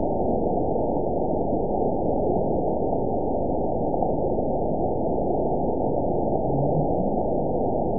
event 920343 date 03/17/24 time 22:15:40 GMT (1 year, 1 month ago) score 9.55 location TSS-AB02 detected by nrw target species NRW annotations +NRW Spectrogram: Frequency (kHz) vs. Time (s) audio not available .wav